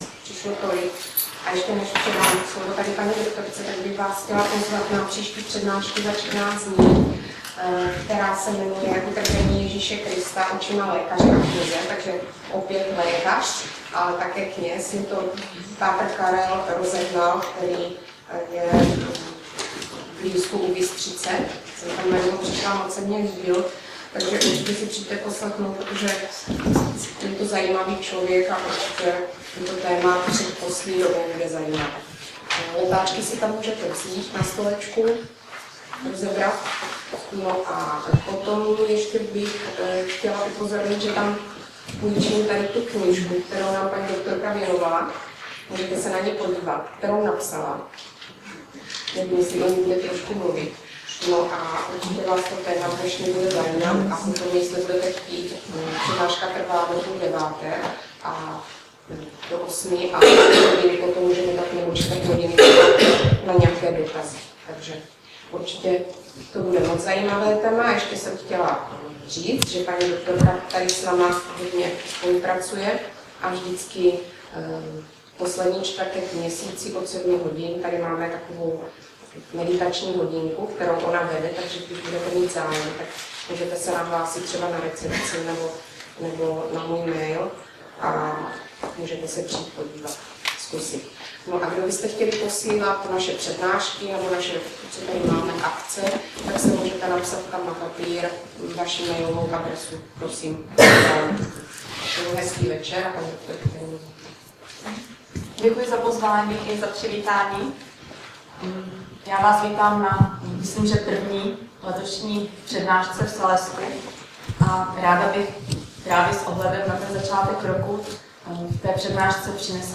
Poučení o výše uvedených otázkách přispívá k odpovědnému rozhodování jednotlivce ve složitých životních situacích. úterý 13. 1. v 19:00 v klubu VeSPOD